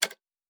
pgs/Assets/Audio/Sci-Fi Sounds/Mechanical/Device Toggle 09.wav
Device Toggle 09.wav